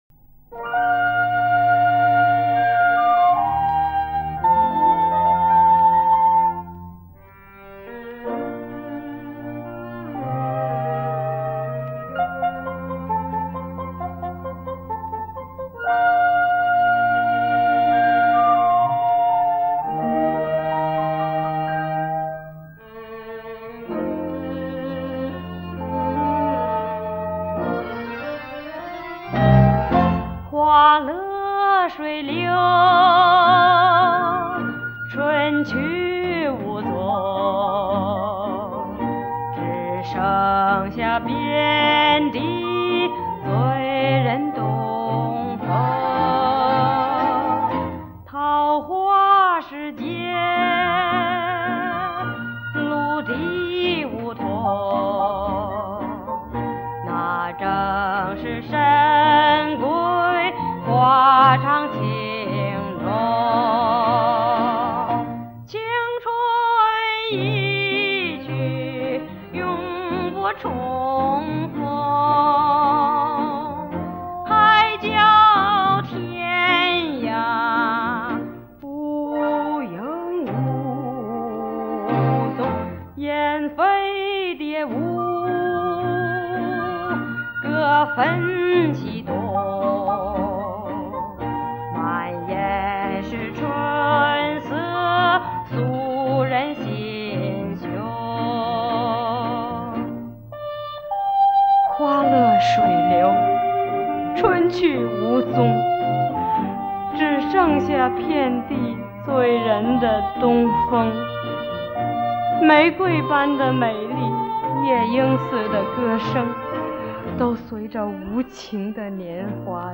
利用最新音频降噪技术
还原最真实的原版录音效果